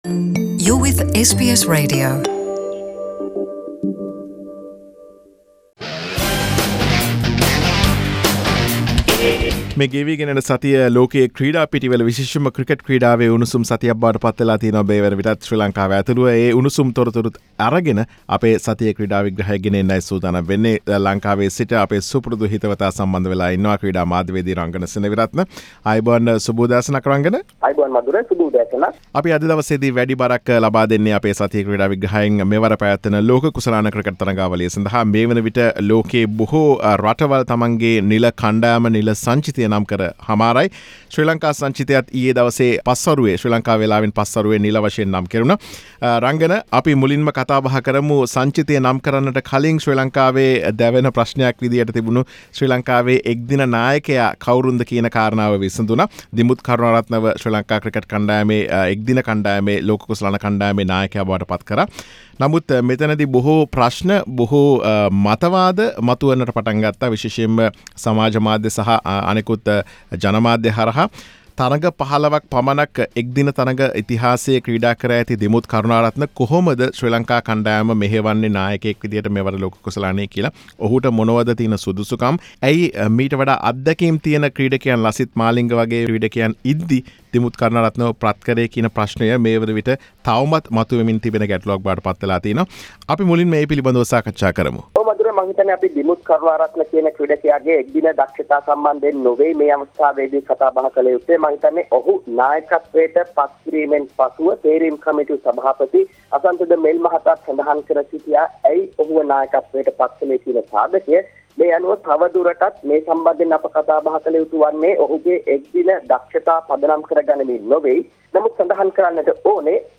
Today’s content: Sri Lankan squad for ICC Cricket World Cup 2019, Dimuth Karunaratne’s appointment as Sri Lanka ODI World Cup team captain, Lasith Mainga’s controversial retirement story, Sri Lanka team for Asian Youth Netball Championship. Sports journalist